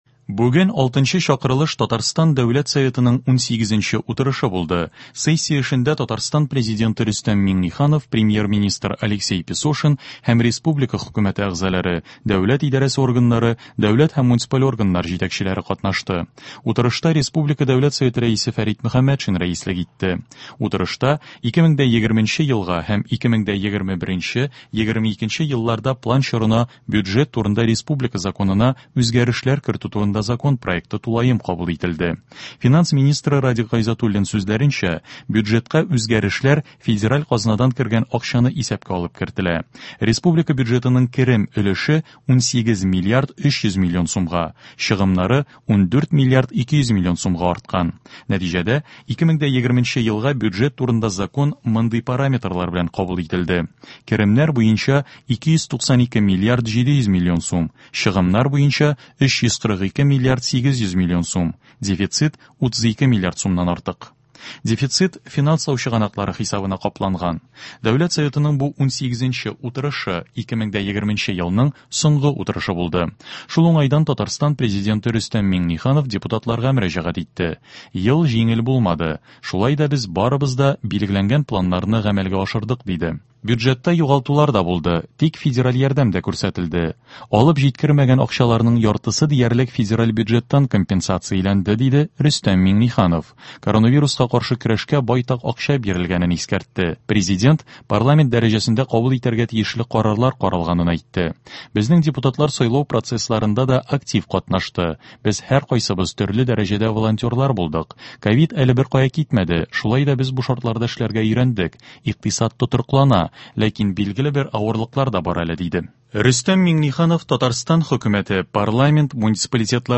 Радиоотчет (23.12.20) | Вести Татарстан
В эфире специальный информационный выпуск , посвященный 18 заседанию Государственного Совета Республики Татарстан 6-го созыва.